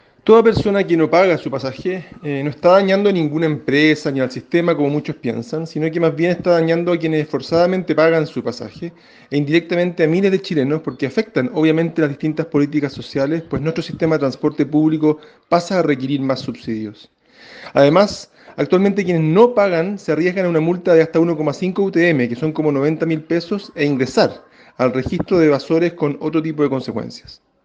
En ese contexto, el ministro Juan Carlos Muñoz, señaló que estas conductas no dañan a las empresas, sino a quienes pagan su pasaje.